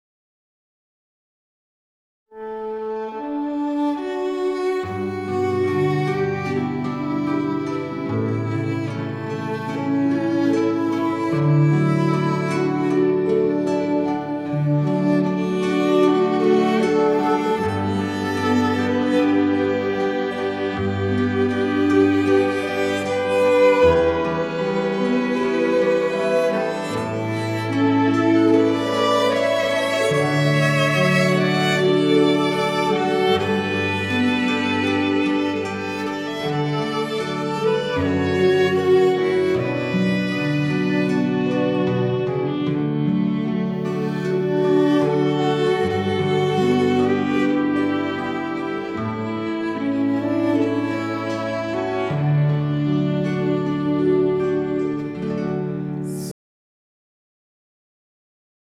ein paar Takte dieser schönen Melodie